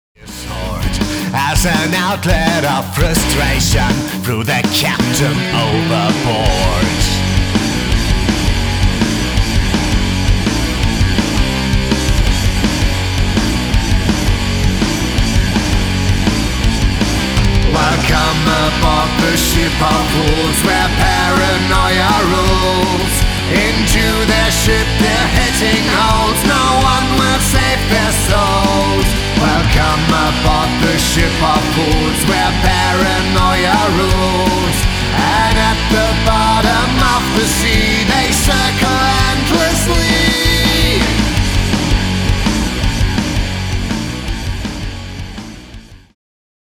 Metal
Vocals
Guitar, Bass
Drums